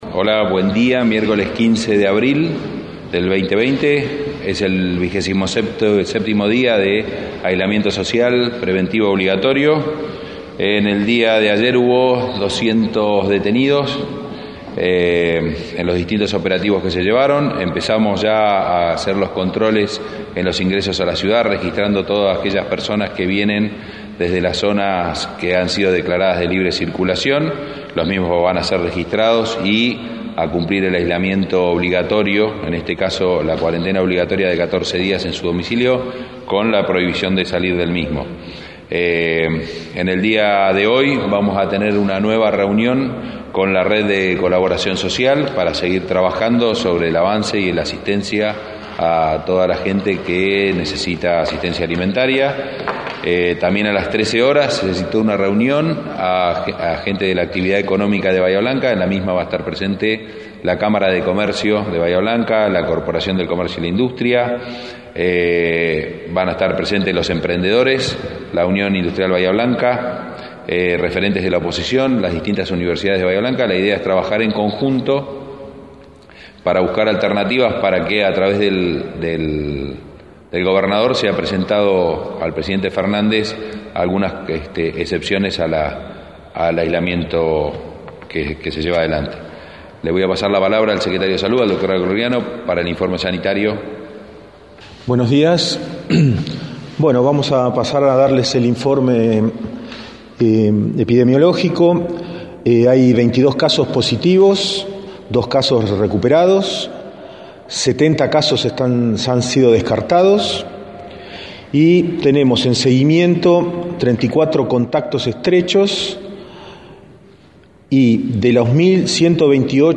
Conferencia de Prensa del Municipio – URBANA 93.9
El Secretario de Gobierno, Adrián Jouglard El Secretario de Salud, Pablo Acrogliano y el Secretario de Movilidad Urbana, Tomás Marisco realizaron una conferencia de prensa para informar sobre el avance del COVID-19 en la ciudad y las nuevas medidas adoptadas.